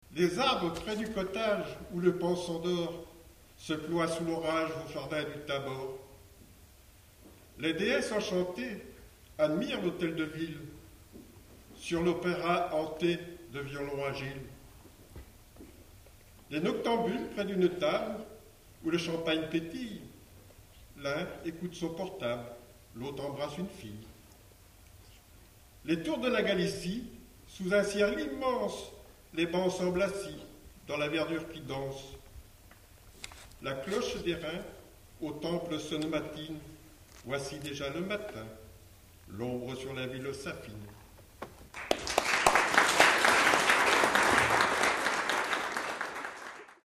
Au bout de la langue - Lectures publiques au triangle
Certains ânnonent quand même, ils ne connaissent pas d'autres méthodes.